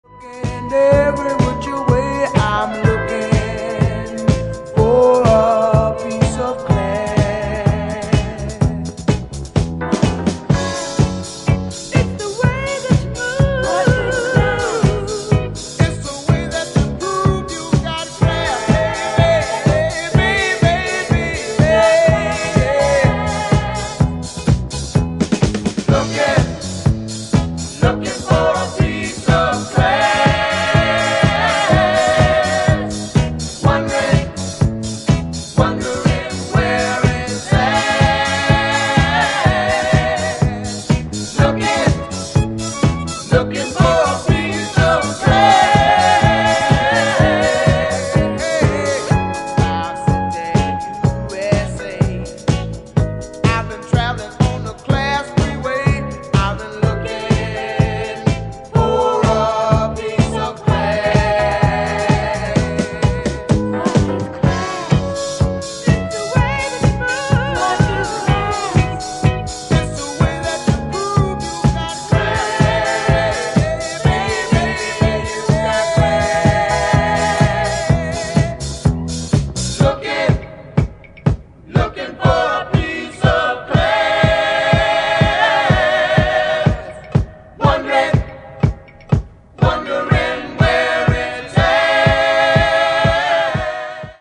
1979年発表のモダンソウル＆ディスコ名曲がジャケ付で嬉しい再発！
多くのトップDJ達から愛され続ける爽快ディスコナンバーです！！
ジャンル(スタイル) DISCO / SOUL